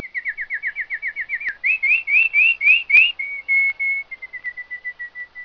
Canary Download
Canary.mp3